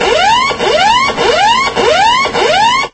engine_alert1.ogg